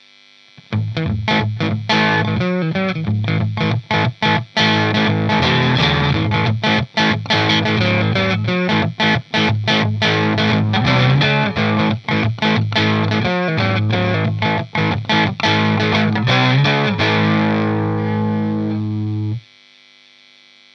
guitare (strat tokai ou melody maker ou westone thunder) -> ampli -> cab 2x12 greenback -> micro shure PG57 -> preamp micro -> numérisation (M audio 1010lt)
un petit bémol pour la prise de son sur le coté "pétillant" des samples en satu, en direct c'est pas "pétillant" de l'aigu.
encore un coup de strat
crunch strat2.mp3